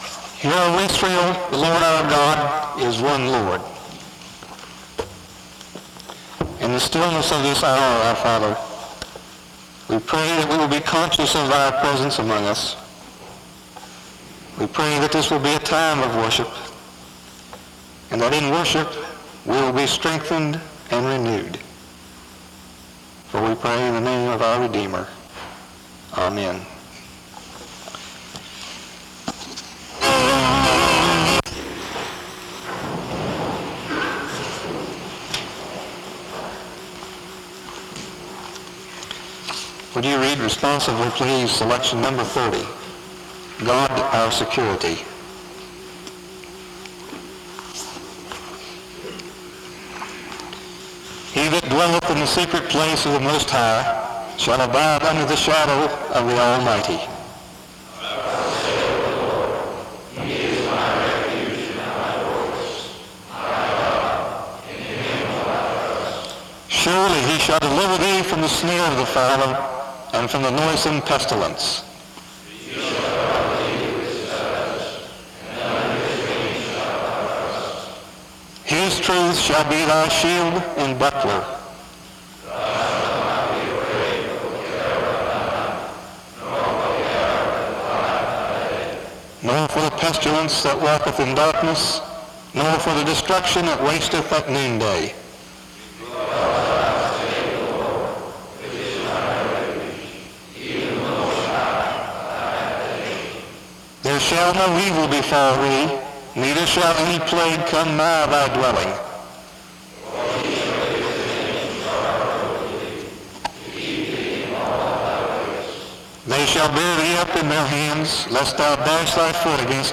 The service starts with a prayer from 0:00-0:26. A responsive reading occurs from 0:39-2:18. Various verses from Joshua 24 are read from 2:28-5:40.